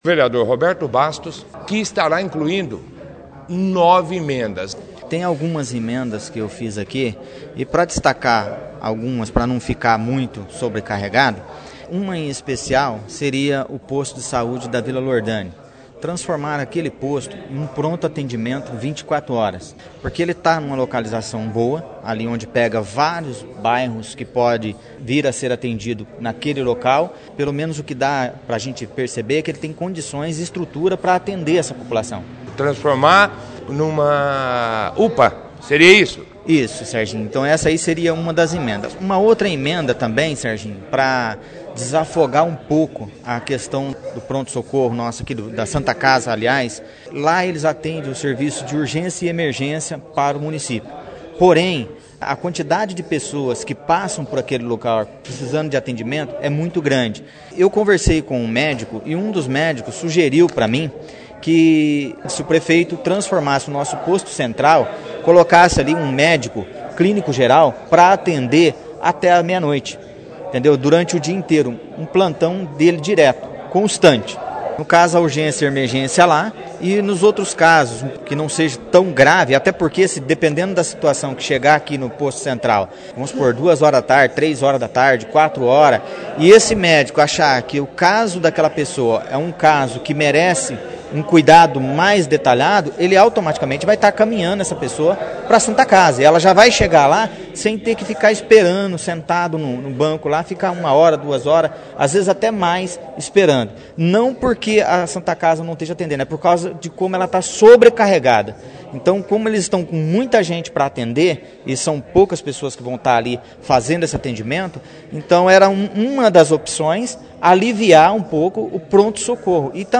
A sessão foi destaque da 2ª edição do jornal Operação Cidade desta terça-feira, 25/06/19, com a participação dos vereadores, Roberto Basto e Jaelson da Mata, que foram os vereadores que mais emendas estão propondo na LDO e do presidente Daniel Gustavo Silva, que falou sobre o encerramento dos trabalhos legislativo do primeiro semestre, já que a sessão ordinária desta segunda-feira, 24/06, foi a última antes do recesso parlamentar de julho.
20ª-Sessão-Camara-de-Vereadores.mp3